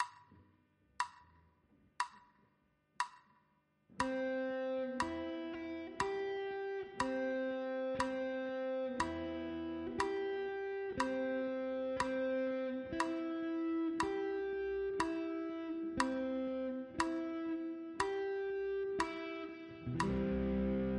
Ex 1 – C-Dur Akkordtöne auf zwei Saiten